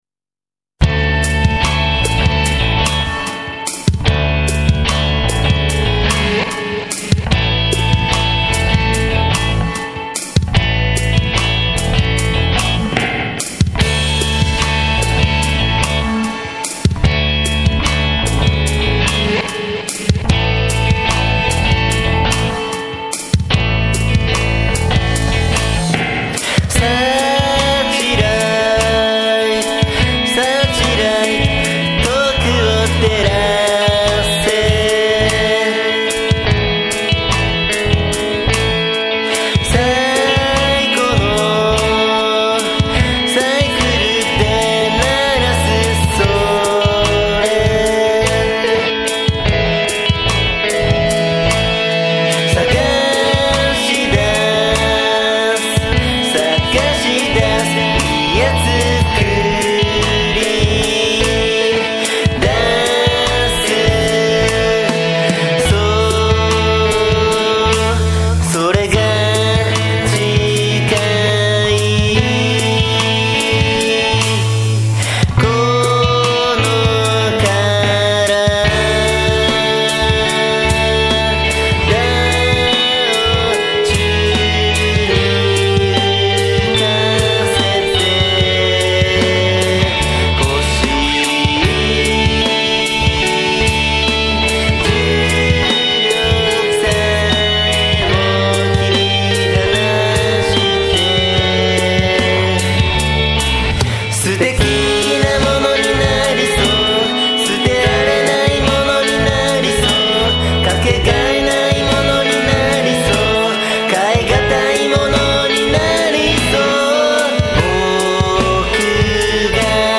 フィードバック音ヲ切リ貼リシテミタ　凝ッテマスヨ　「音楽」ヲ歌ッタ歌